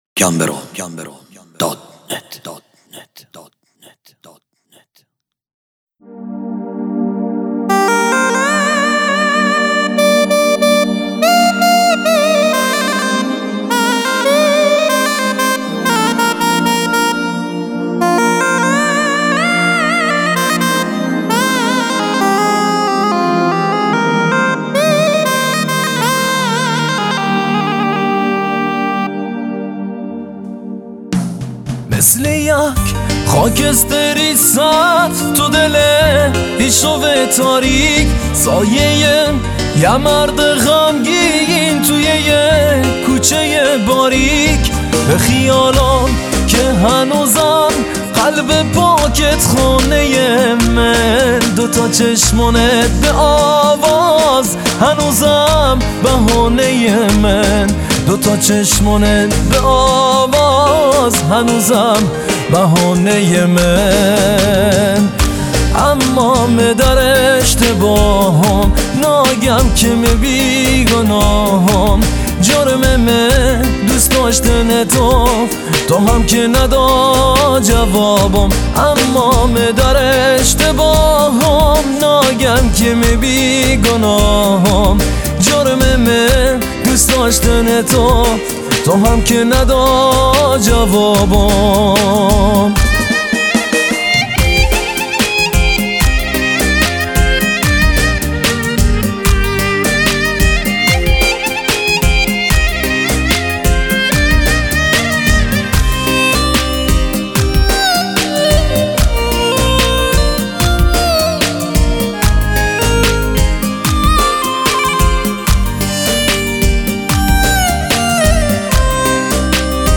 دانلود آهنگ بستکی